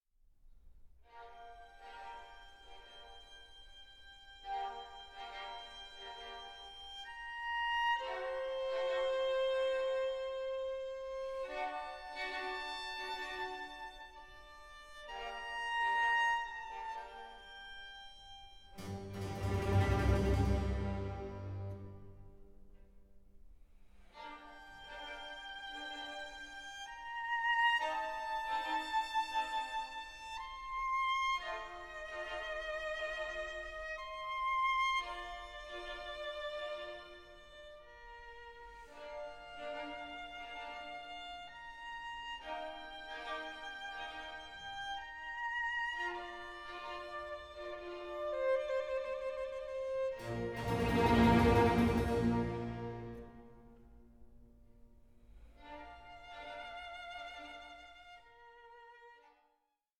LIVELY COMBINATION OF BAROQUE AND TANGO
violin and string orchestra